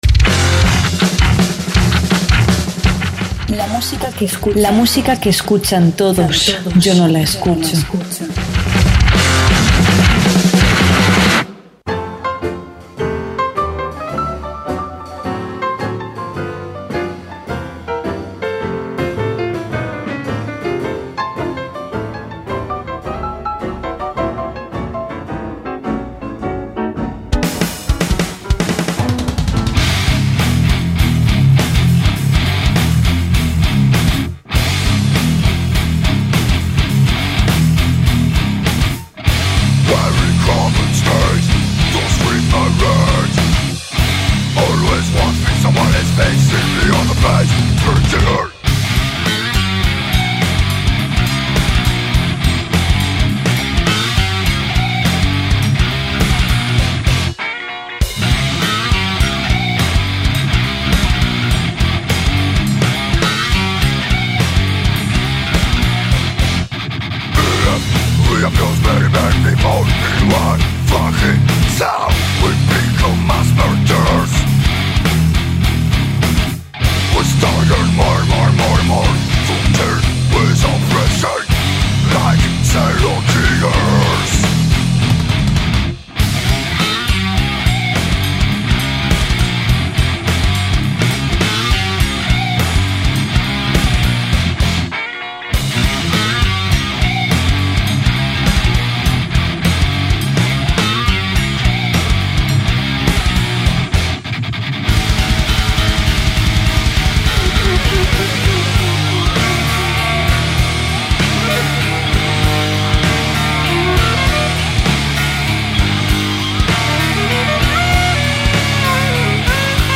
metal sinfónico